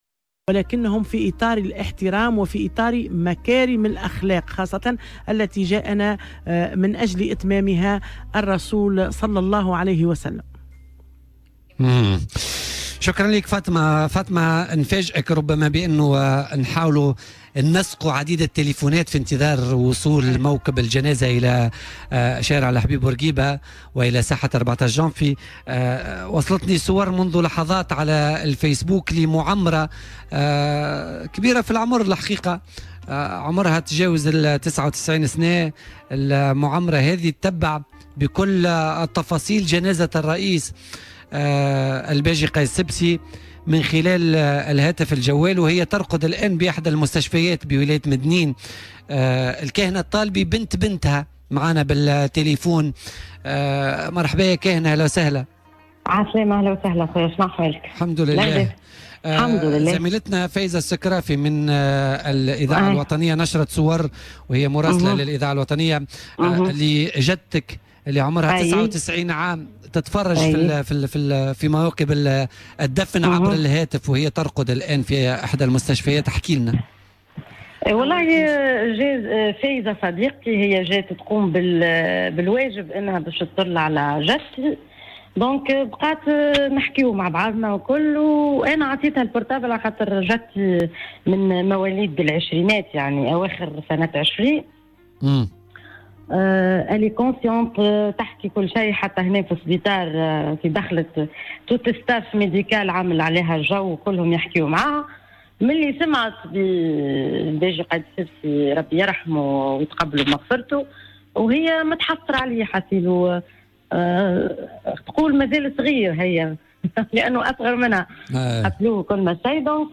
تألمت لرحيله : شهادة معمّرة طريحة الفراش تابعت جنازة الباجي عبر الجوال
مداخلة هاتفية